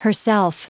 Prononciation du mot herself en anglais (fichier audio)
Prononciation du mot : herself